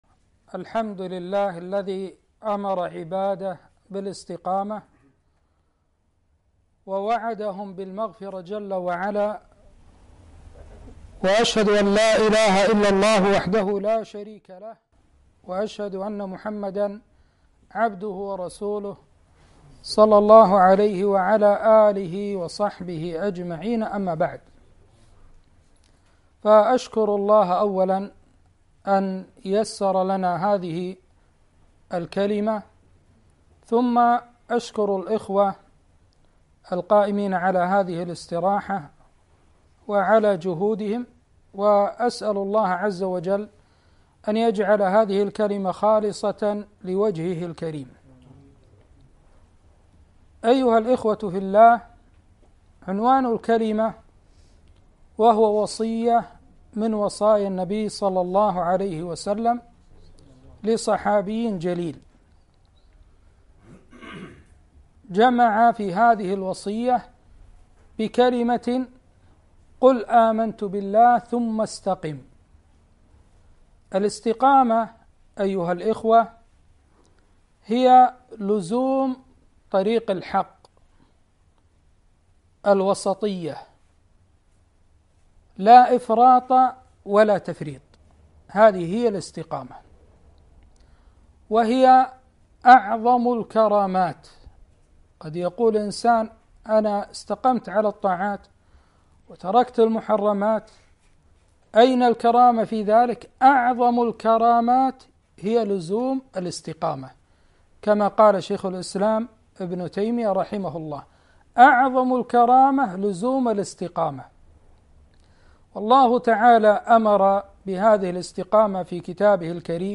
محاضرة - ( قل آمنت بالله ثم استقم )